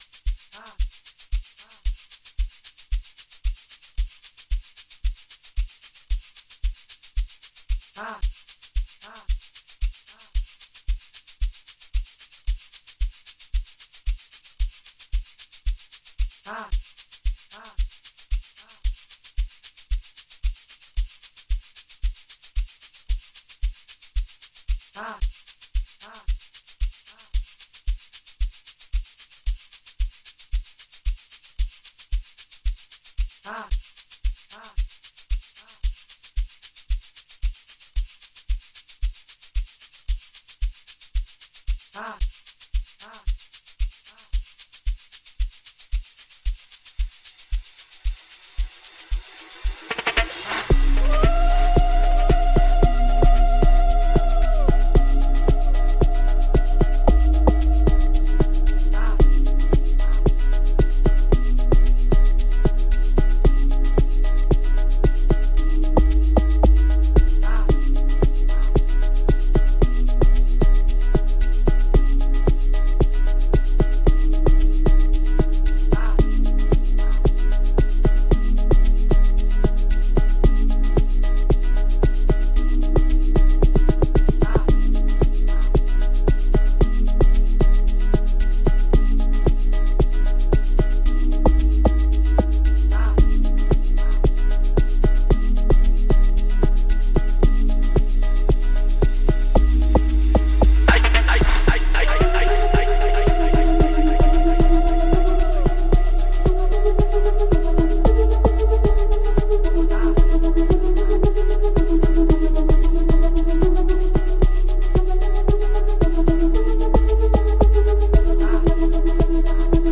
05:24 Genre : Amapiano Size